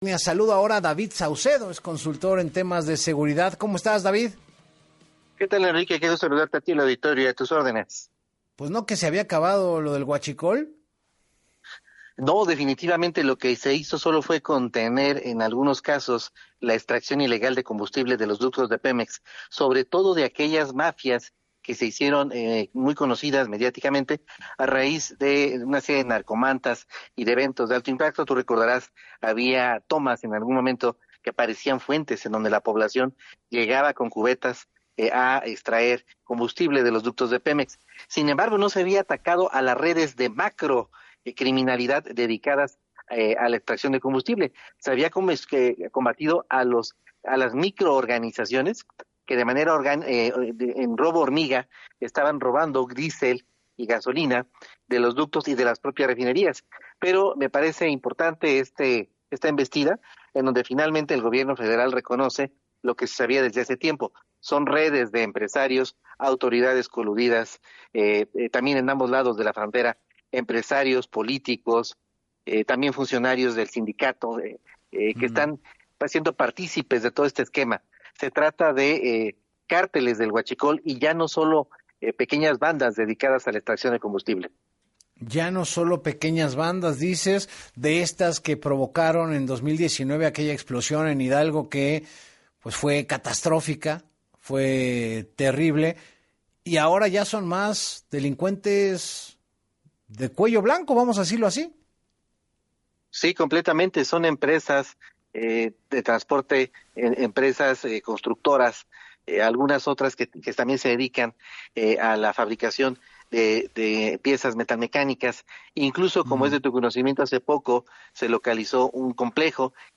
el especialista en seguridad